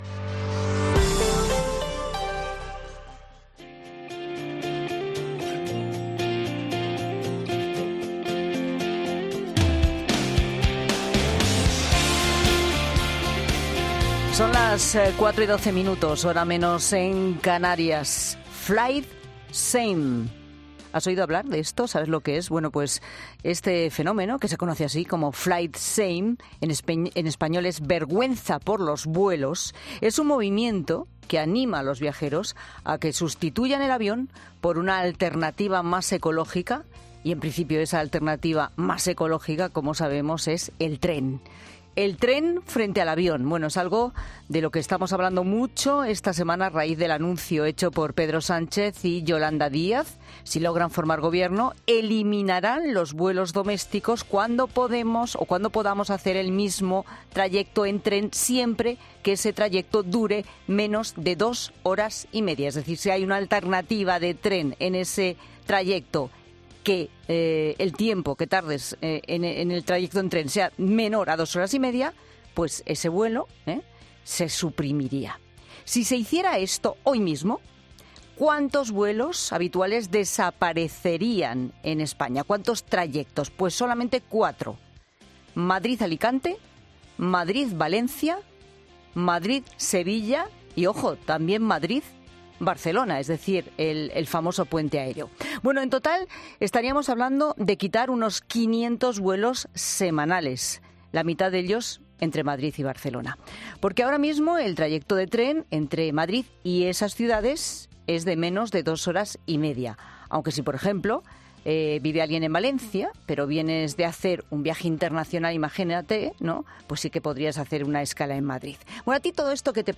En la 'La Tarde' hemos tenido la oportunidad de hablar con